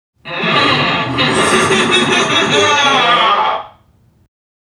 NPC_Creatures_Vocalisations_Robothead [43].wav